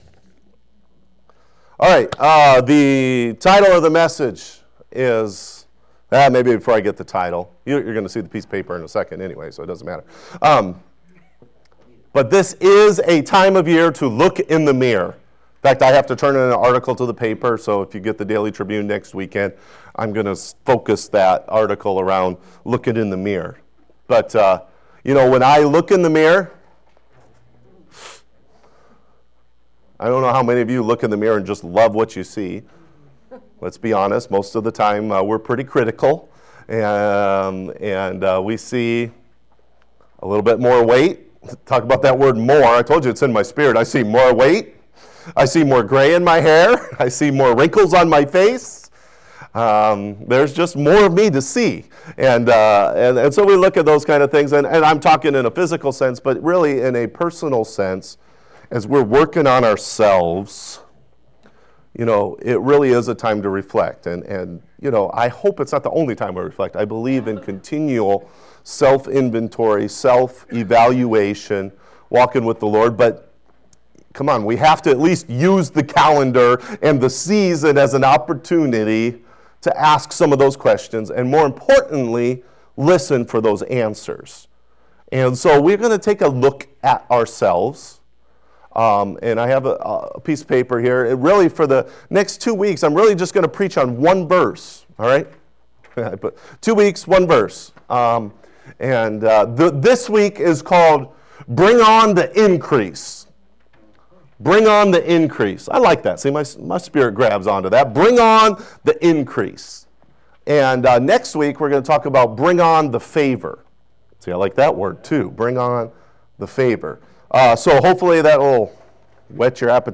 Sermon Archive - Life Worship Center